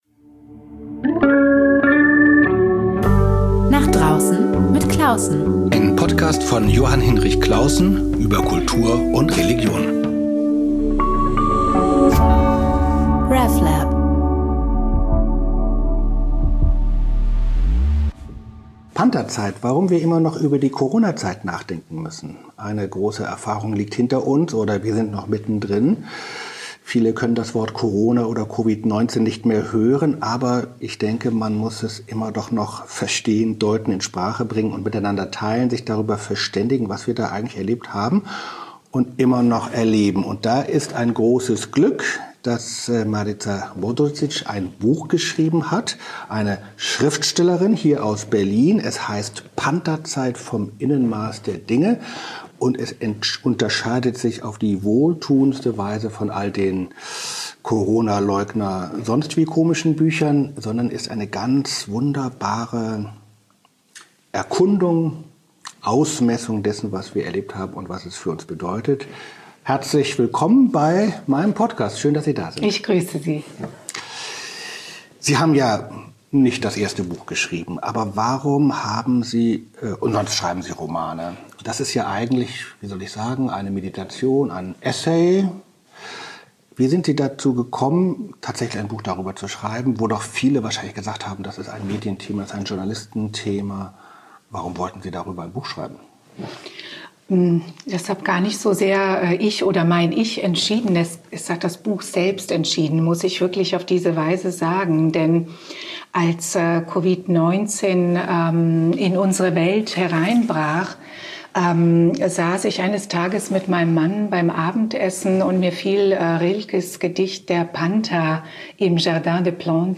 Nicht als journalistisches Frage-Antwort-Spiel, sondern als gemeinsames, ernsthaft-unterhaltsames Nachdenken.